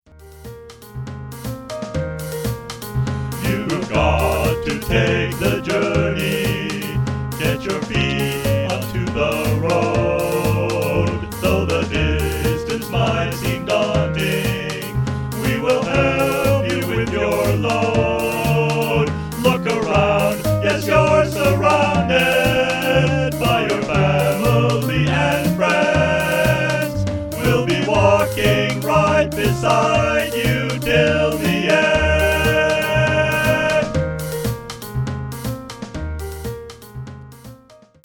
Here are some rough demos of a few of the songs:
The closing number is an anthem about how growing up means stepping out, experiencing life, and meeting people, even if you’re scared to do it.